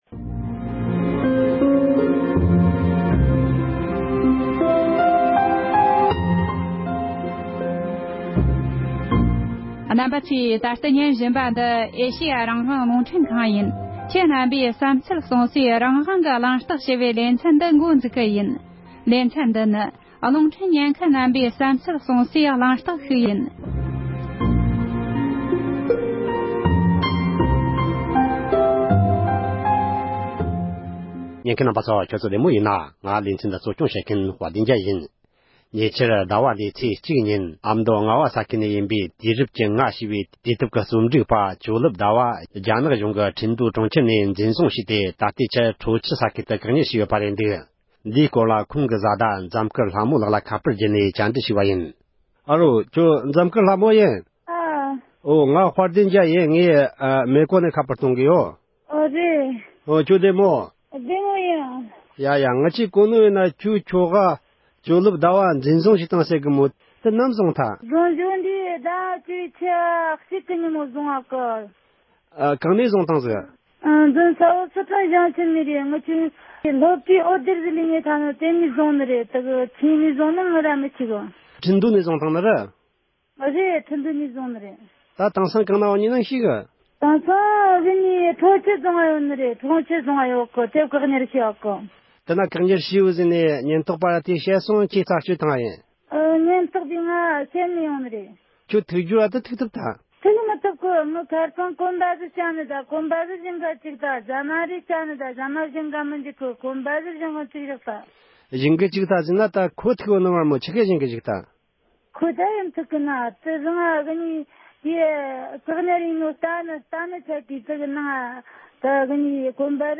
ཁ་པར་བརྒྱུད་བཅར་འདྲི་བྱས་པར་གསན་རོགས་ཞུ།